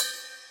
Crashes & Cymbals
Ride Groovin 1.wav